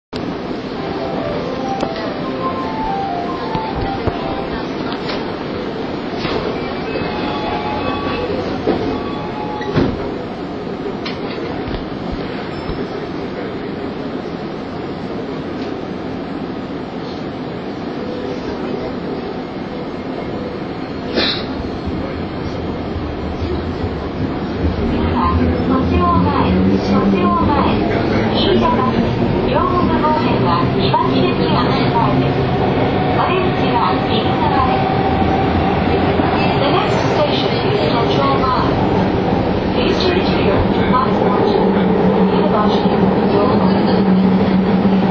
車内放送・構内放送
新宿発車
収録機器/レコーダー：オリンパス ボイストレック V51　　　マイク：オーディオテクニカ　AT9842
※各ファイルはビットレートを圧縮しています。このため音質が低下しています。